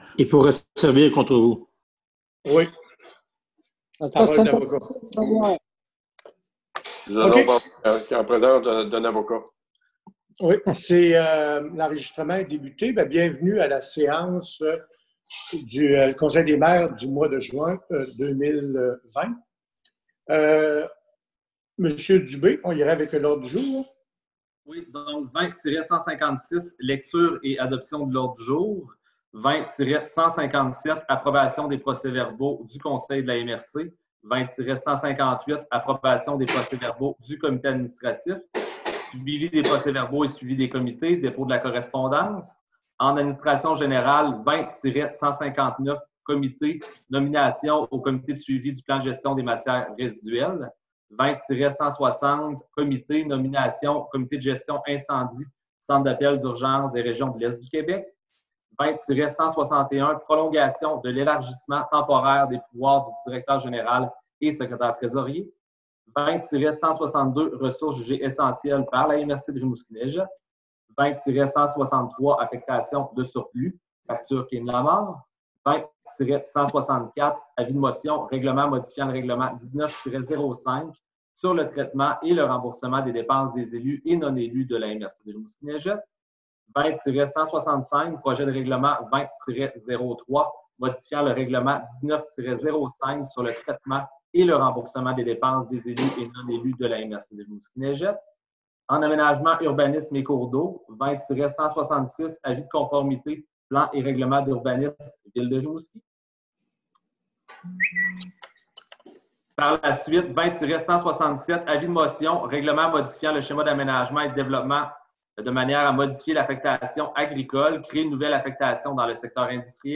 Voici l’enregistrement audio de la séance du conseil de la MRC du 10 juin 2020.